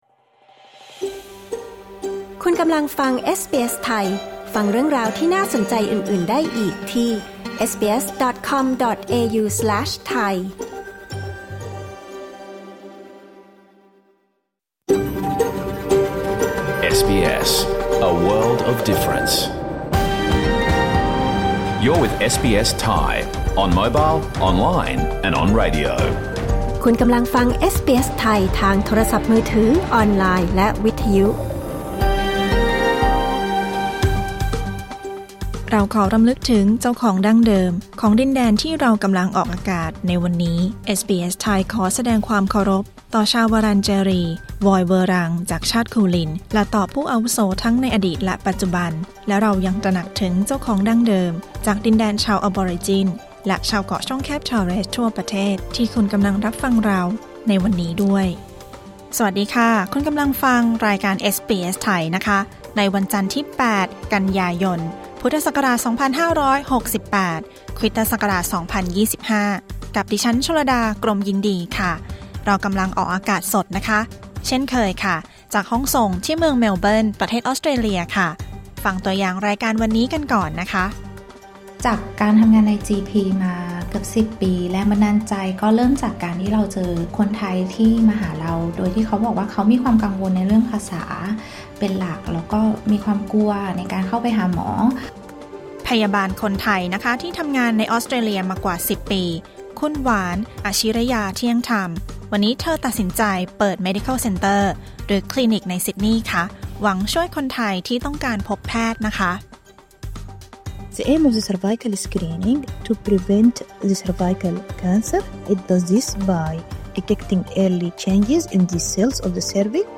รายการสด 8 กันยายน 2568